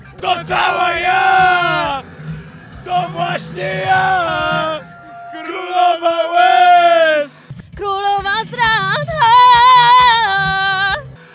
Podczas wieczornego show Agnieszki Chylińskiej żacy bawili się śpiewająco!
śpiewy.mp3